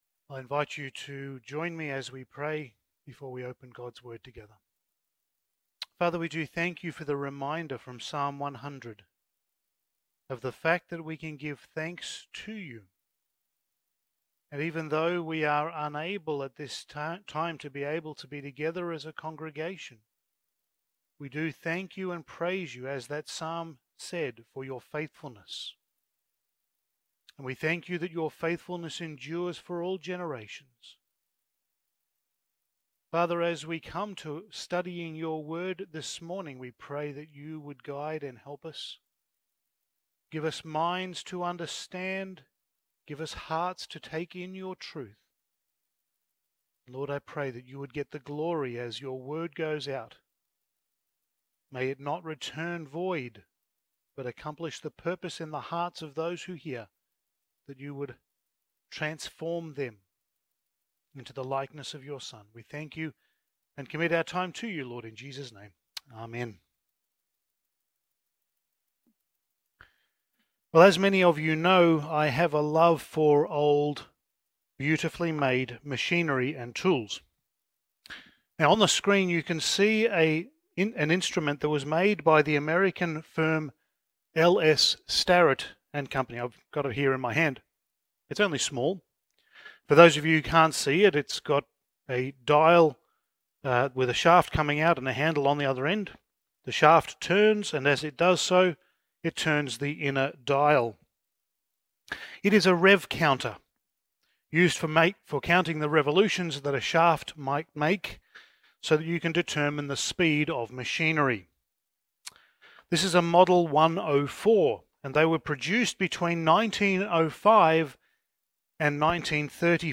Passage: Acts 9:1-19 Service Type: Sunday Morning